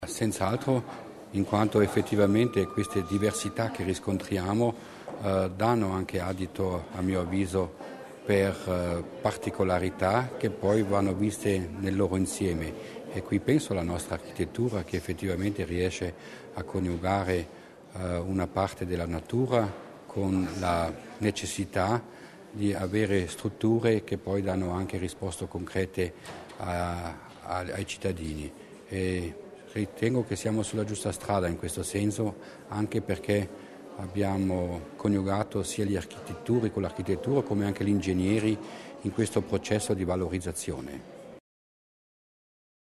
L’Assessore Provinciale Mussner sul valore dell’iniziativa